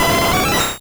Cri de Voltali dans Pokémon Rouge et Bleu.